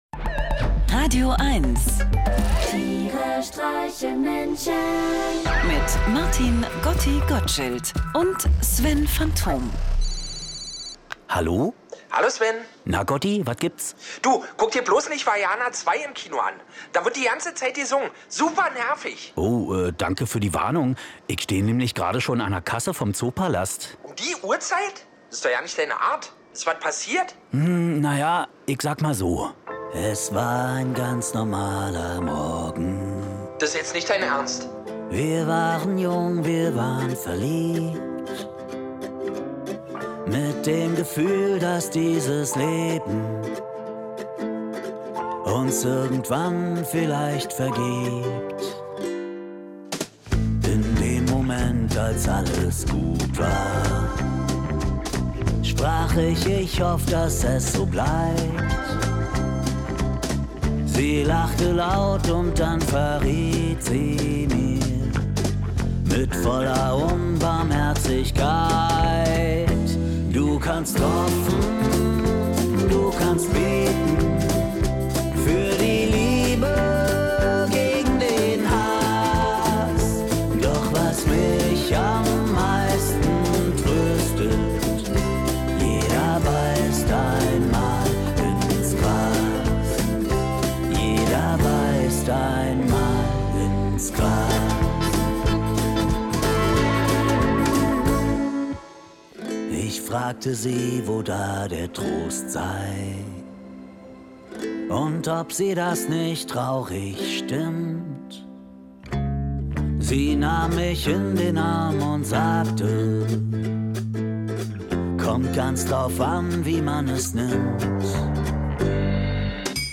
Einer liest, einer singt und dabei entstehen absurde, urkomische, aber auch melancholische Momente.